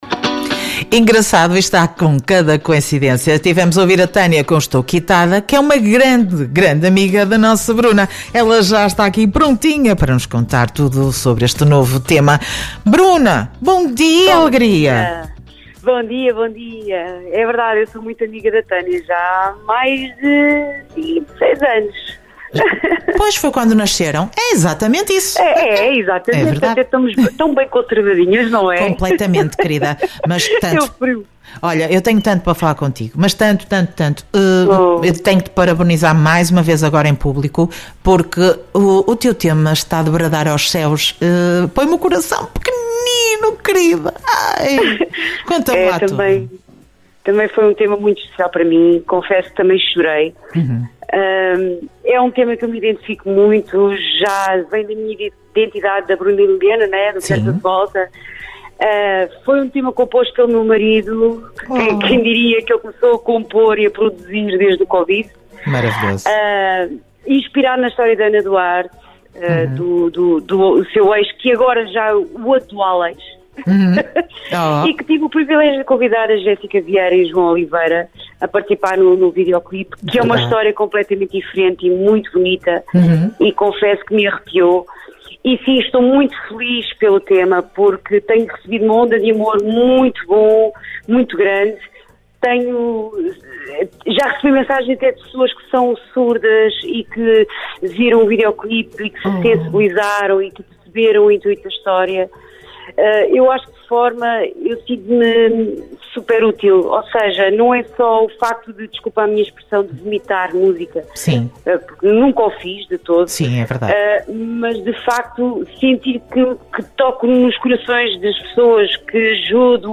Entrevista em direto no programa “Manhãs NoAr”